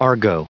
added pronounciation and merriam webster audio
1715_argot.ogg